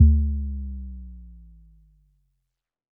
Bass Power Off 9.wav